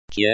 入声字有声教材，参照了很多的地方方言，本贴的目的只是让大家更好的欣赏古诗，我每添加一个字，都会尽量去各方求证她发音的正确性，所以，请别讨论发音是否准确的问题，谢谢。
字：杰，发音：